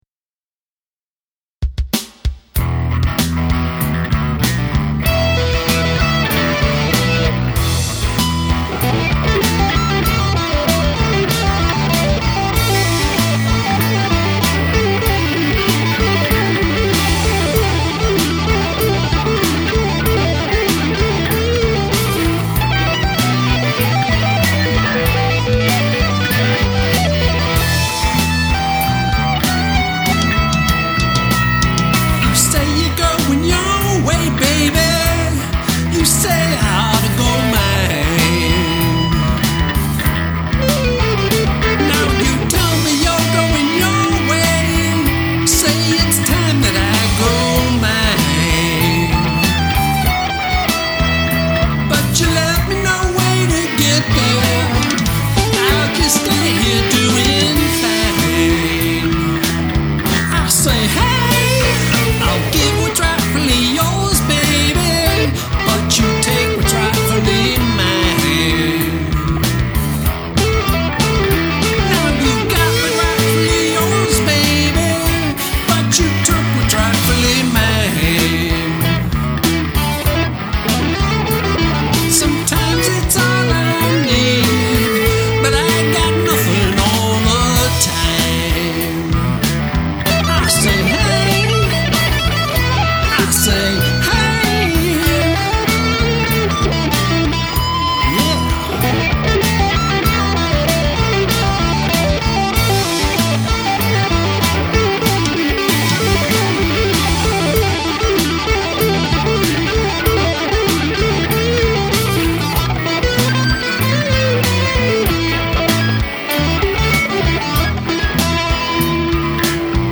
Blues jam